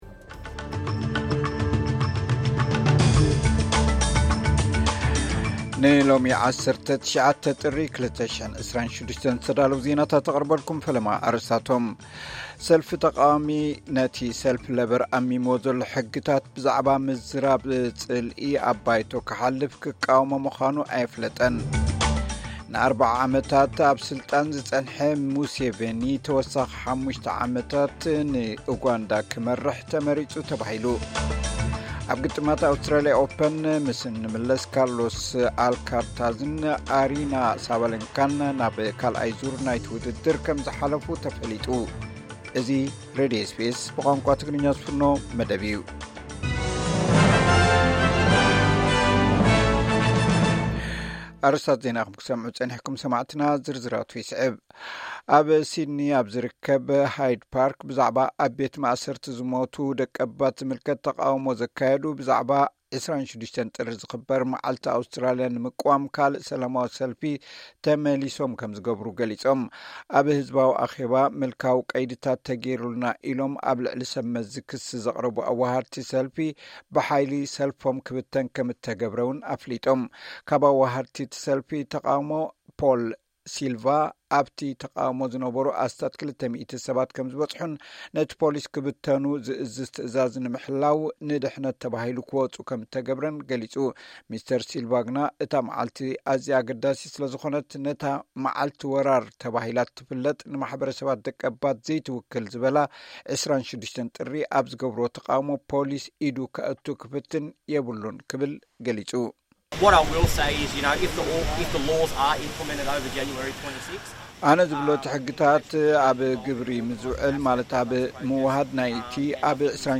ዕለታዊ ዜና ኤስ ቢ ኤስ ትግርኛ (19 ጥሪ 2026)